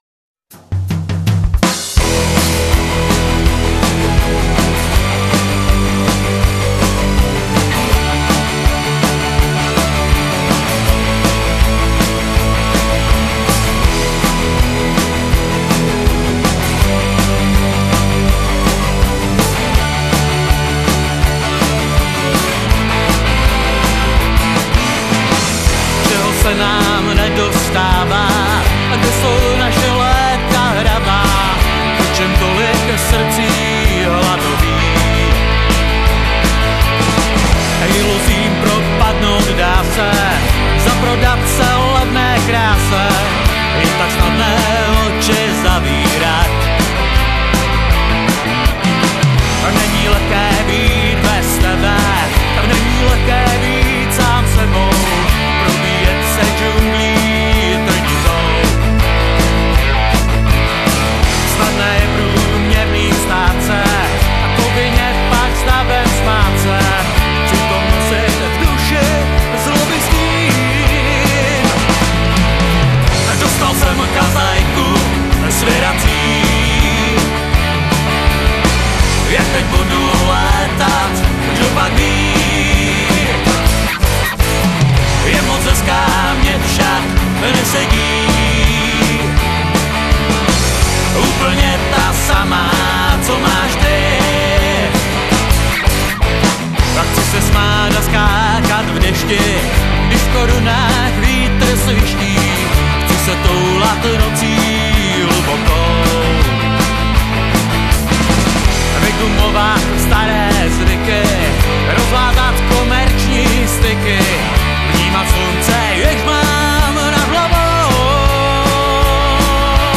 poslední studiovou nahrávkou
klávesový doprovod